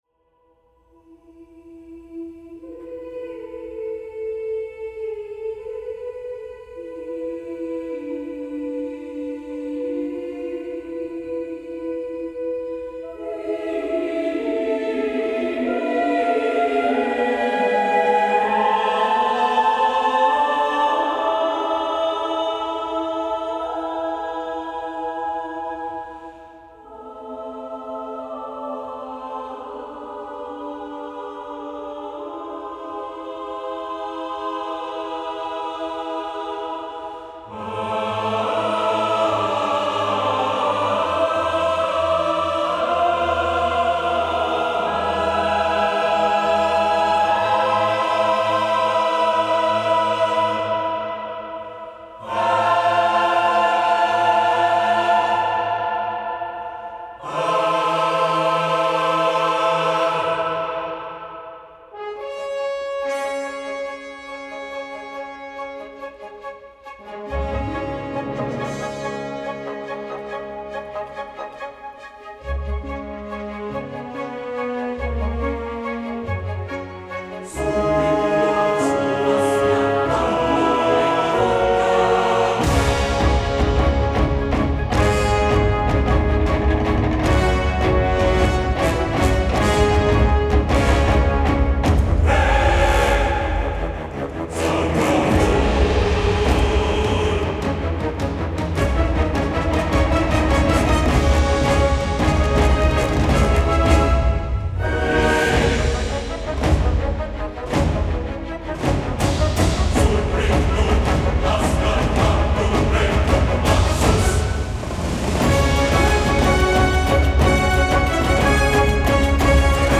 Feature Film & Game Music Excerpts (5mins)